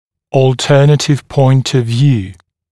[ɔːl’tɜːnətɪv pɔɪnt əv vjuː][о:л’тё:нэтив пойнт ов вйу:]альтернативная точка зрения